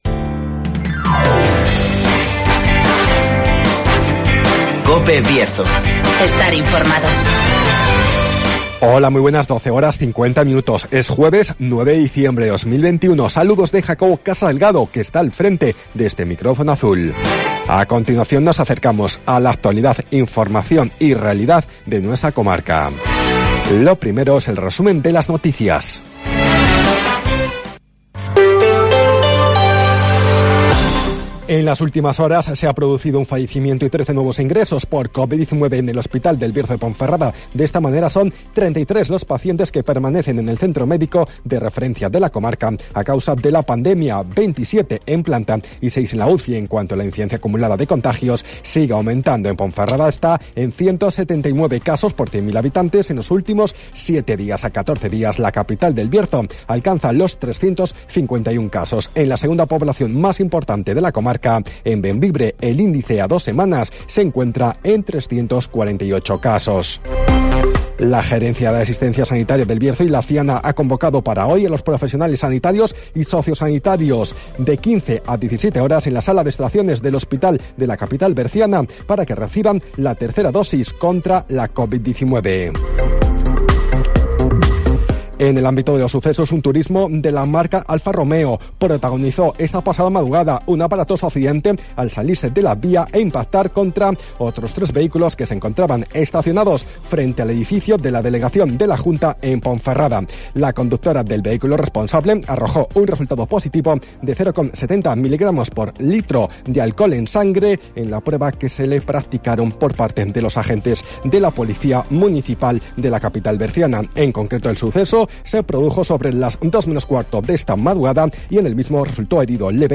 Avance informativo, El Tiempo y Agenda (el resto de días)